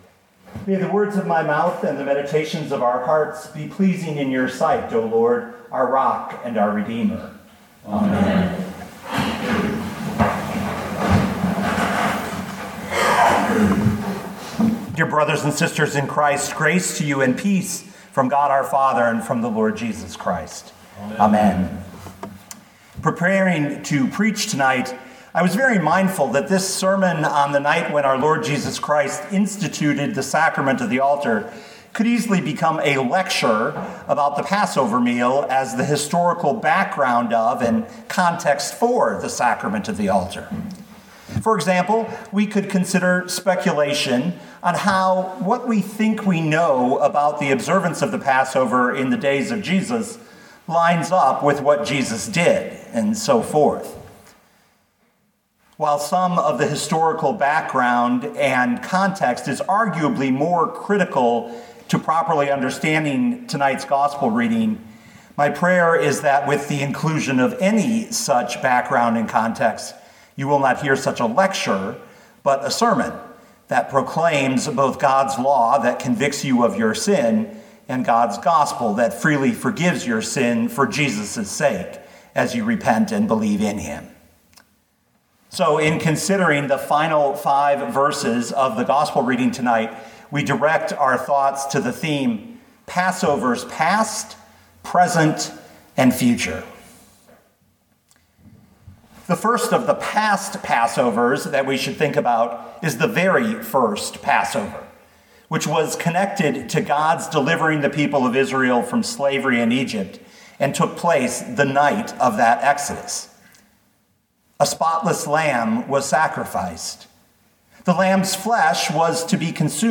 Sermons
Holy (Maundy) Thursday, April 01, 2021